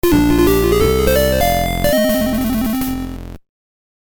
Jingle when clearing a bonus room